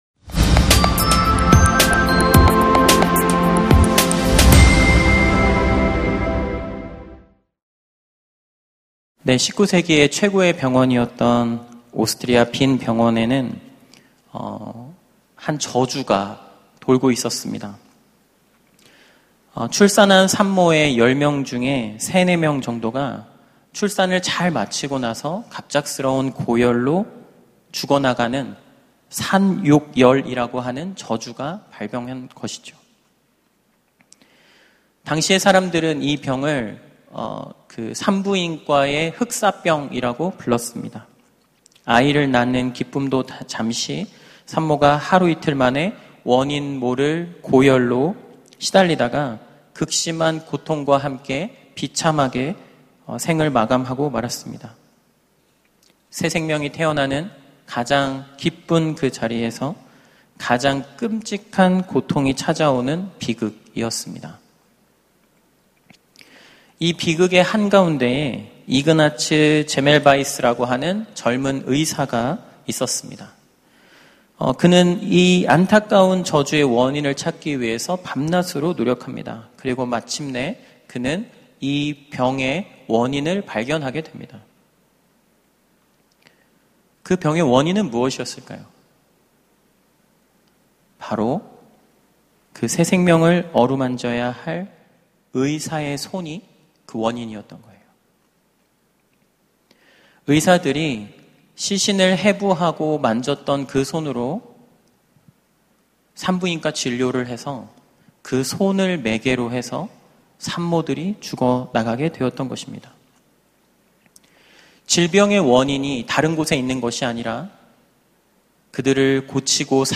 설교 : 파워웬즈데이